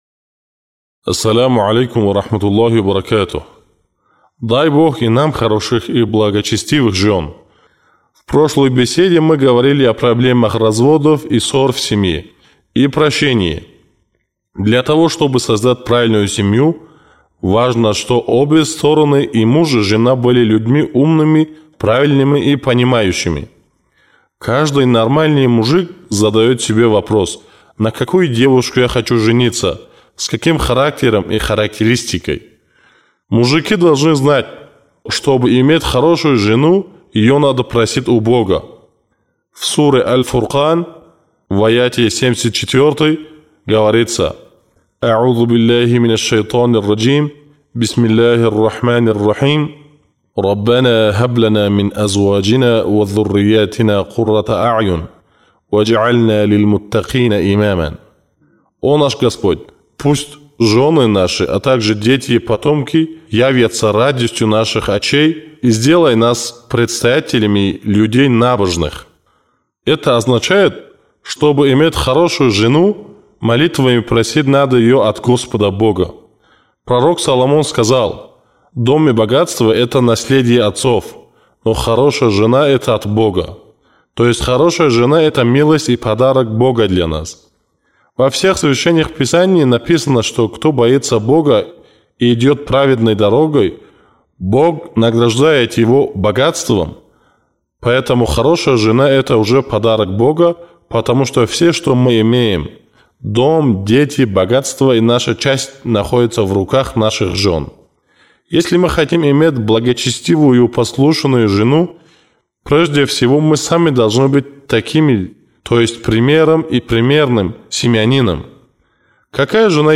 Что нужно знать, чтобы не ошибиться в выборе и найти подходящего и верного супруга? Чтобы найти ответ на этот вопрос, можно прослушать девятую речь «Хороший супруг».